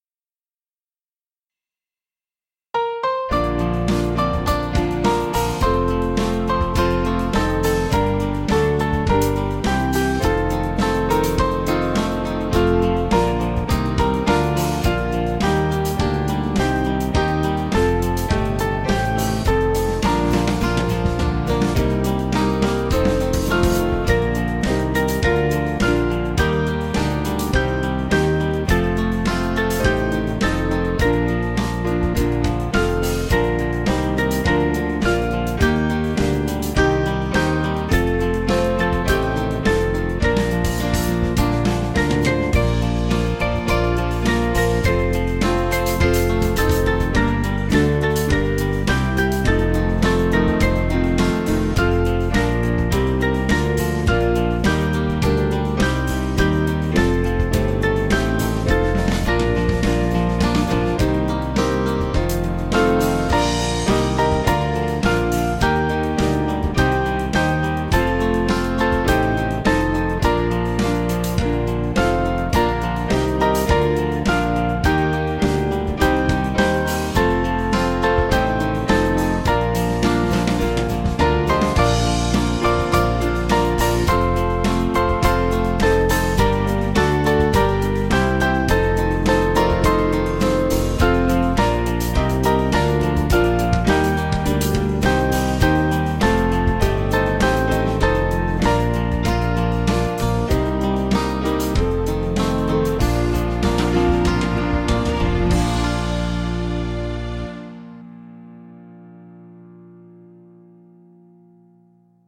Small Band
(CM)   2/Bb 535.1kb